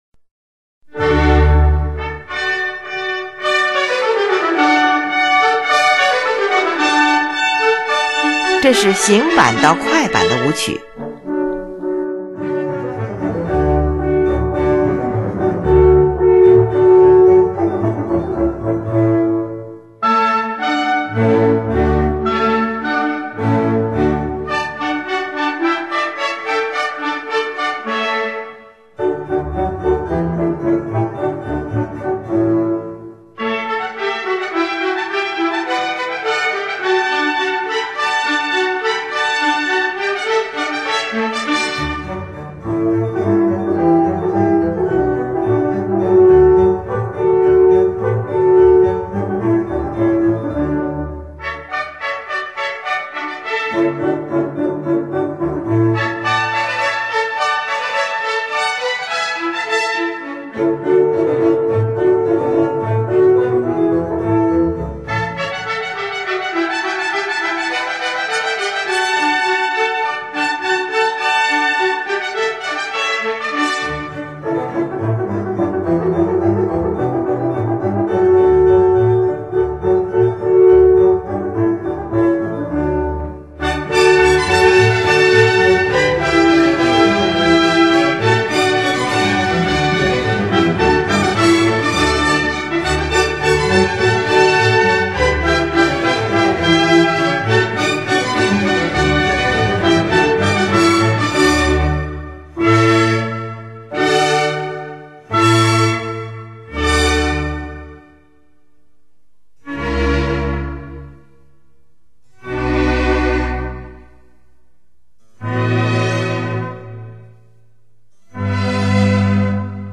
行板，“行走、进行”的意思。
这里说行板到快板，就是指音乐的演奏速度由行进式逐步变为快速。
是一部管弦乐组曲
乐器使用了小提琴、低音提琴、日耳曼横笛、法兰西横笛、双簧管、圆号、小号等